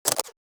camera_click.ogg